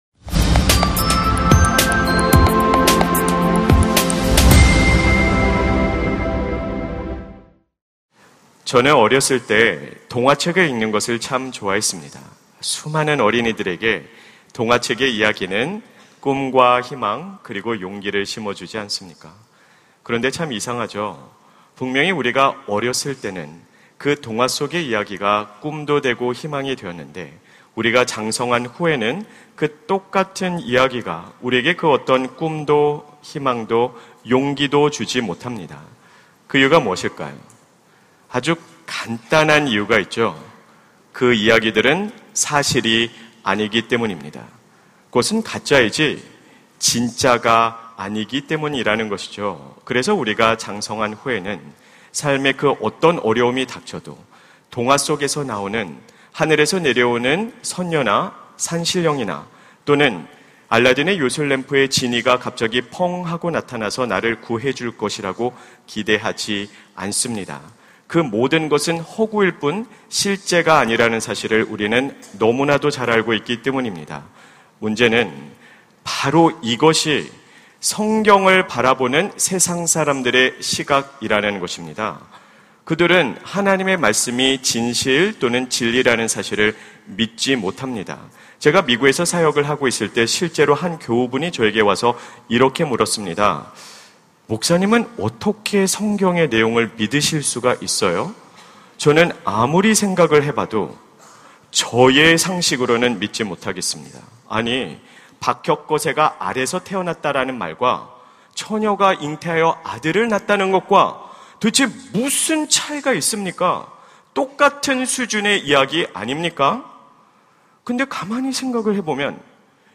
설교 : 수요향수예배 하나님의 숨결이 스며드는 일상 - 정말 체험해 보셨나요? 설교본문 : 요한1서 1:1-4